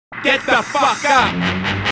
рэп
Хип-хоп